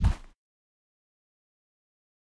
PixelPerfectionCE/assets/minecraft/sounds/mob/guardian/land_idle2.ogg at mc116
land_idle2.ogg